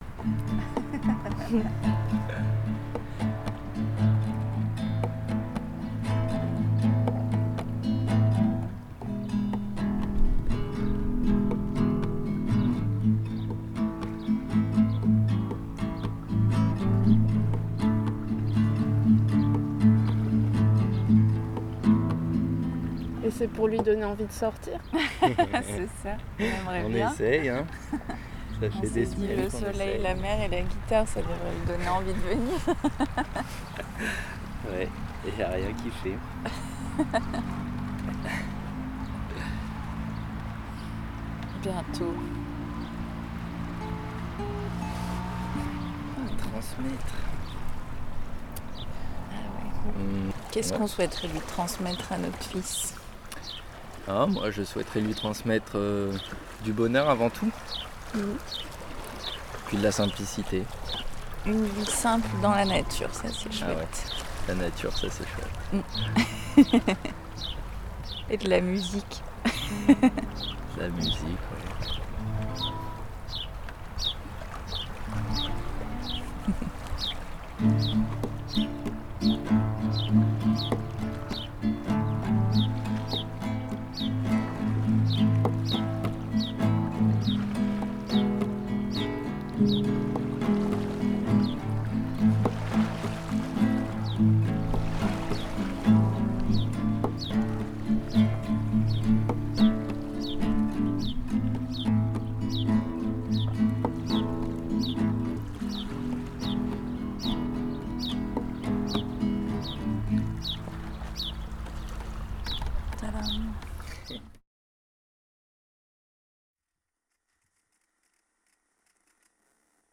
Après deux semaines à bord du Tétrodon, une architecture modulaire située au bord de l’étang de Berre à Martigues, je vous propose d’écouter une capsule sonore réalisée sur place.
Les habitant.e.s de Martigues témoignent de ce qui leur importe, de leur rapport à l’environnement et partagent leurs préoccupations liées à la pollution, au réchauffement climatique notamment.